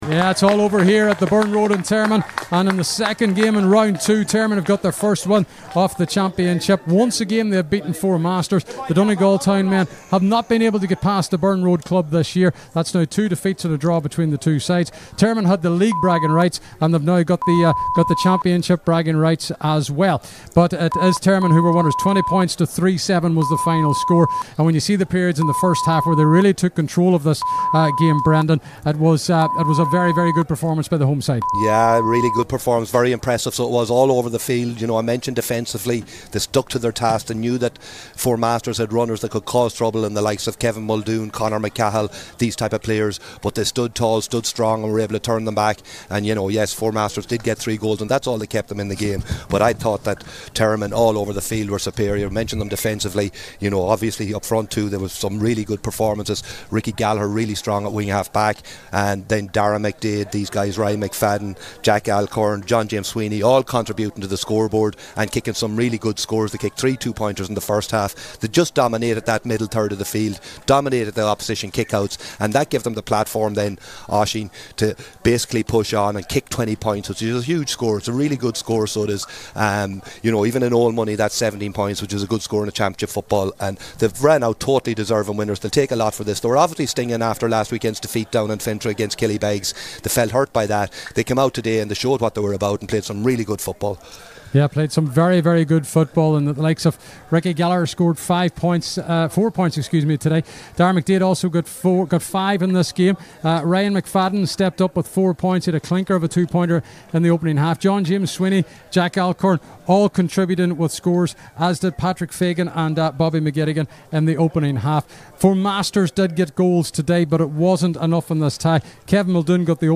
live at full time for Highland Radio Sunday Sport…